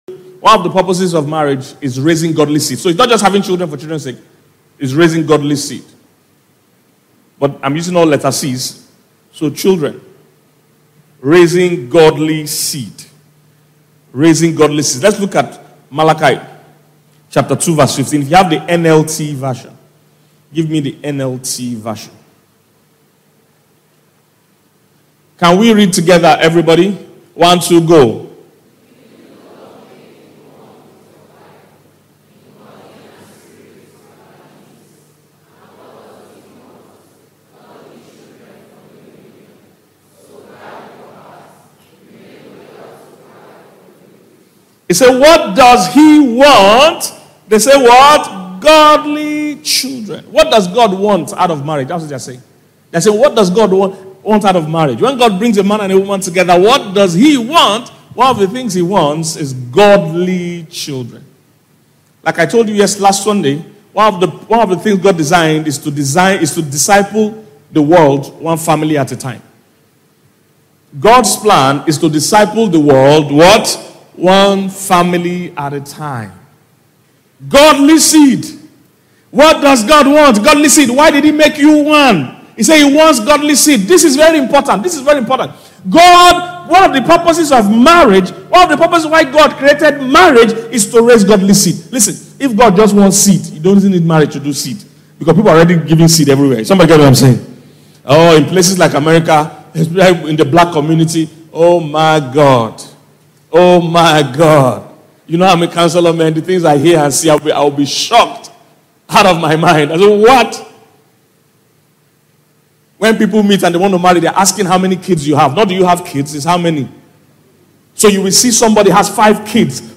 Here’s a message/sermon that is set to change your life.